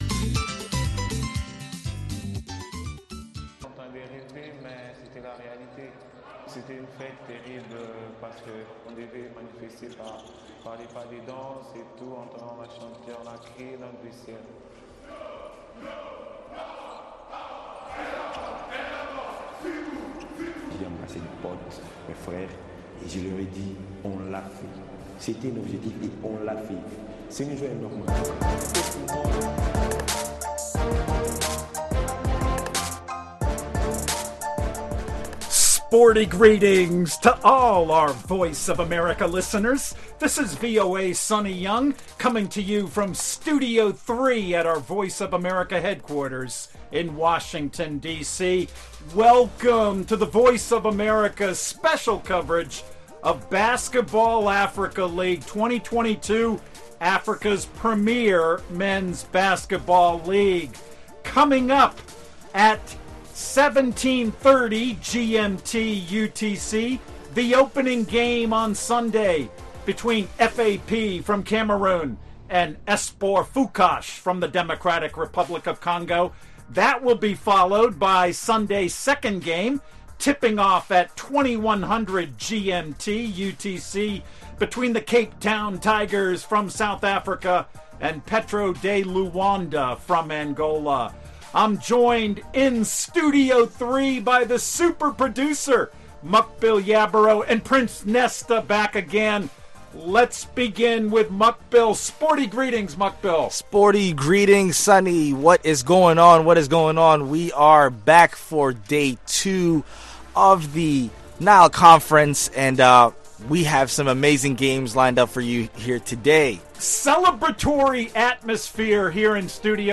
Basketball Africa League Game Play: F.A.P (Cameroon) vs Espoir Fukash (DRC)